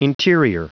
Prononciation du mot interior en anglais (fichier audio)
Prononciation du mot : interior